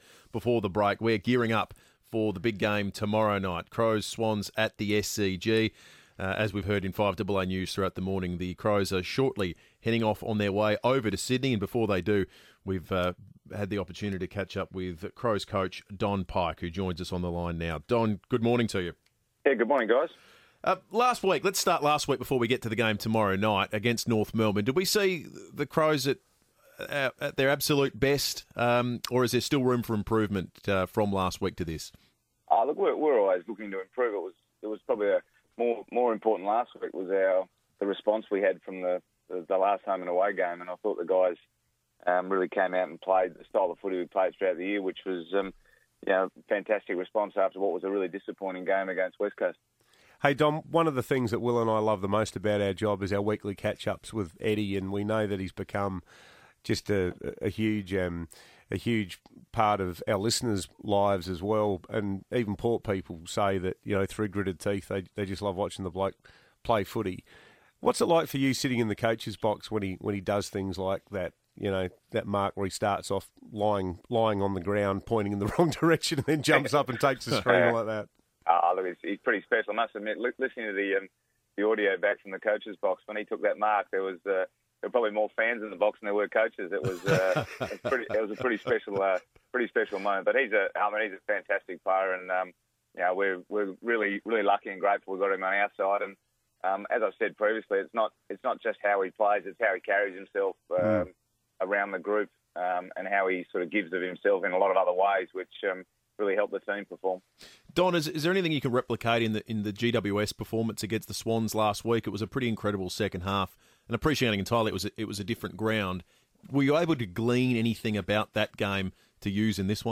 Adelaide coach Don Pyke on FIVEaa ahead of Saturday's semi-final clash with Sydney.